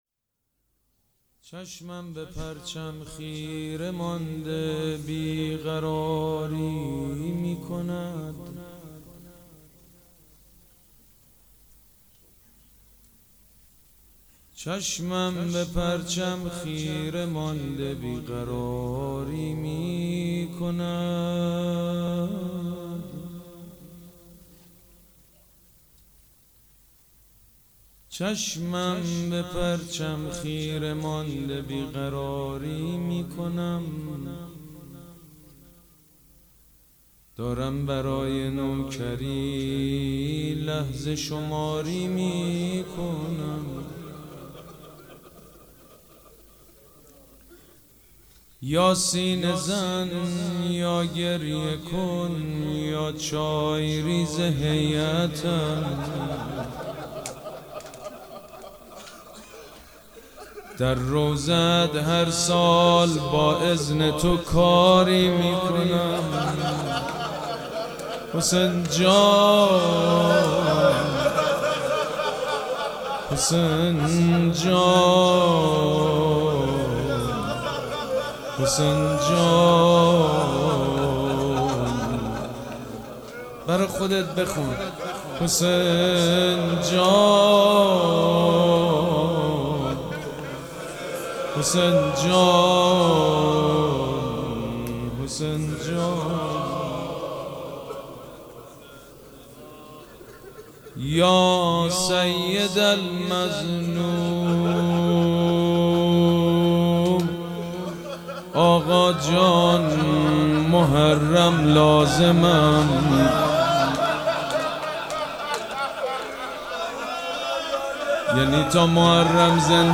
مراسم عزاداری شهادت امام محمد باقر و حضرت مسلم سلام‌الله‌علیهما
شعر خوانی
مداح
حاج سید مجید بنی فاطمه